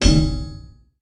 goldenpig_hit_02.ogg